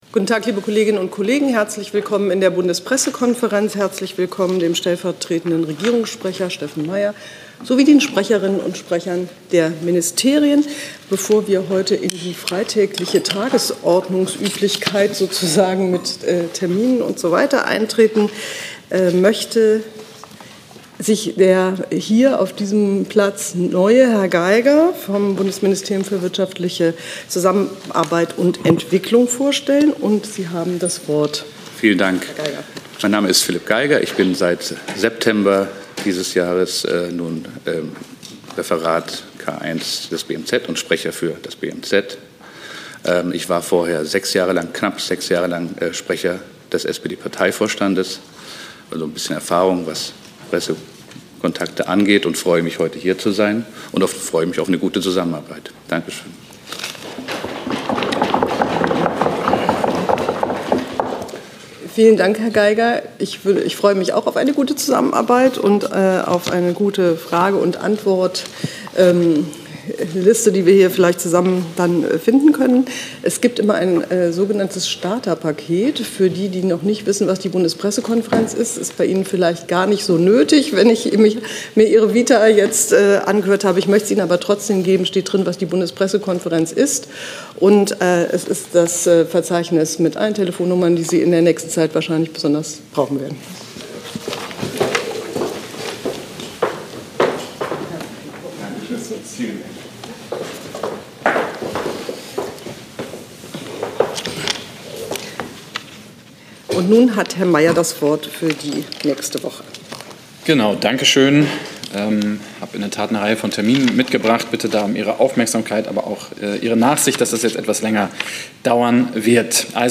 Komplette Regierungspressekonferenzen (RegPK) und andere Pressekonferenzen (BPK) aus dem Saal der Bundespressekonferenz.